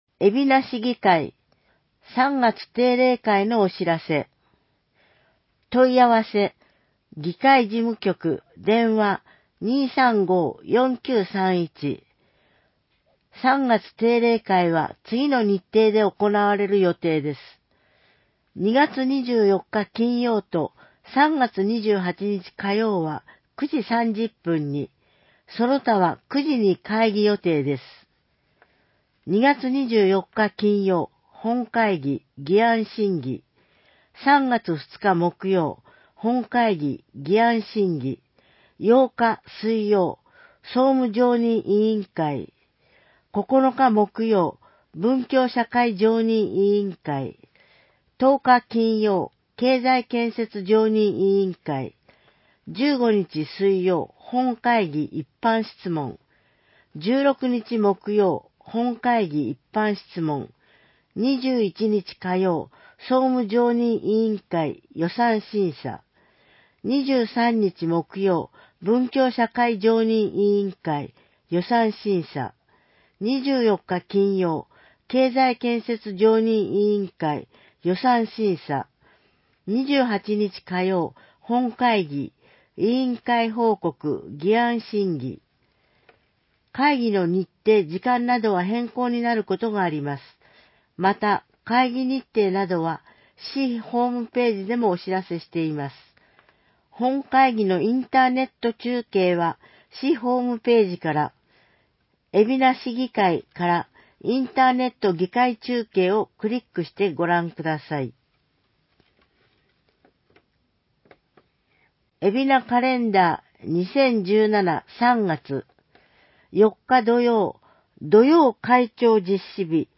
広報えびな 平成29年2月15日号（電子ブック） （外部リンク） PDF・音声版 ※音声版は、音声訳ボランティア「矢ぐるまの会」の協力により、同会が視覚障がい者の方のために作成したものを登載しています。